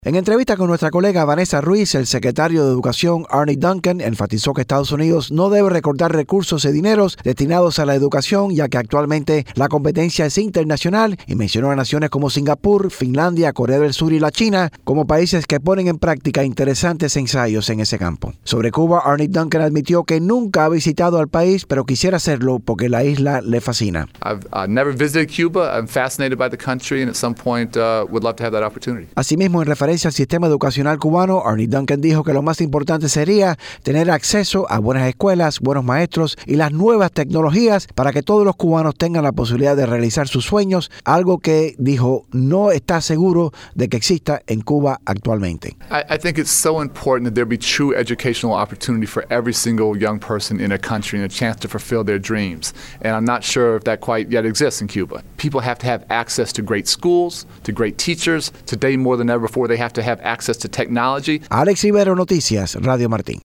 En entrevista exclusiva con TV Marti durante la convenciòn del partido Demócrata, celebrada la semana pasada en Charlotte, Carolina del Norte, el secretario de Educación de Estados Unidos, Arne Duncan hizo referencia a sistema educacional de Cuba.